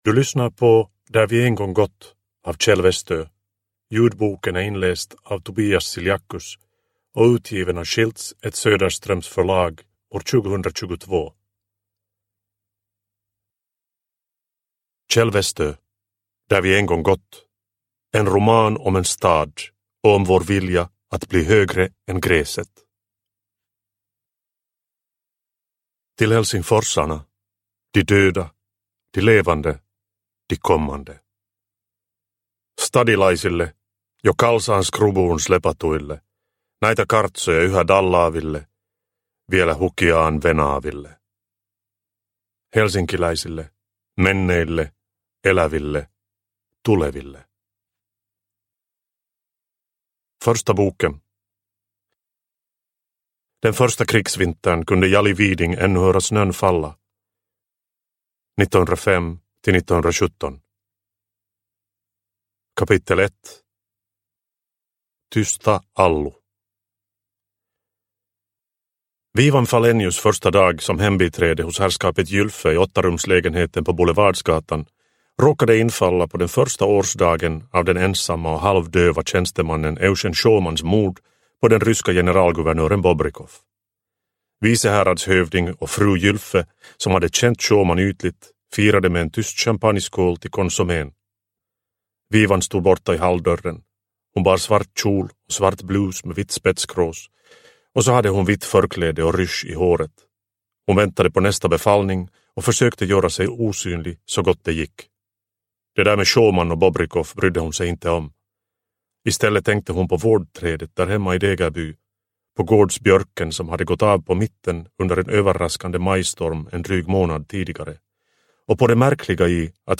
Där vi en gång gått – Ljudbok – Laddas ner